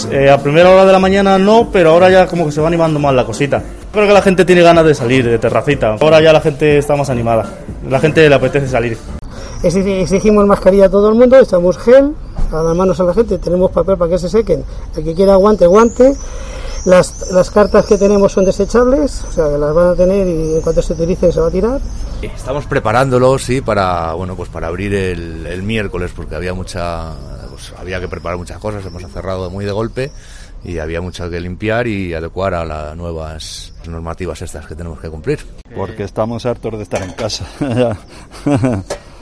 He hablado con los hosteleros y han comentado al microfono de COPE cómo estan preparando sus negocios.